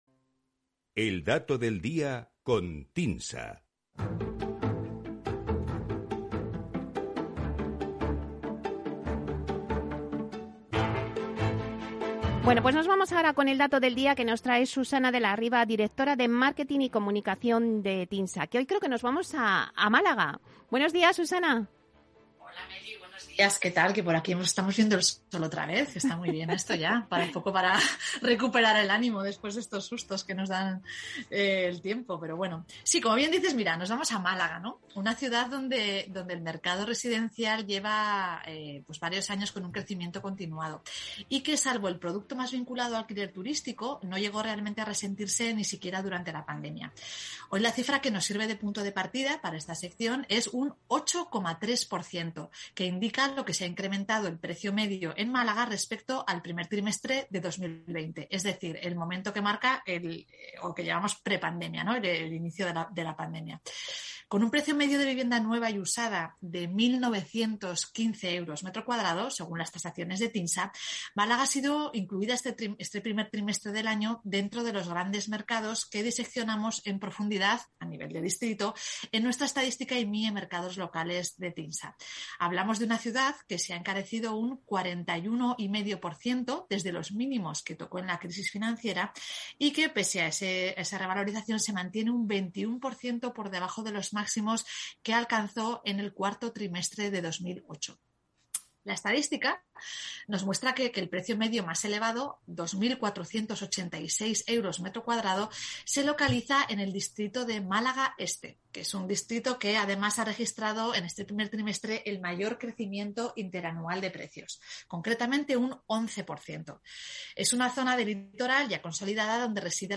Así lo hemos comentado en la sección El Dato de Capital Radio: